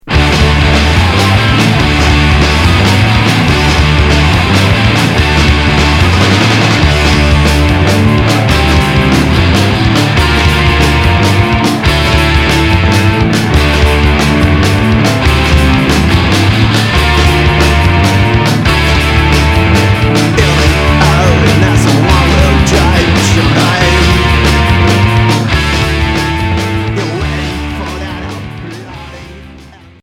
Rock Troisième 45t retour à l'accueil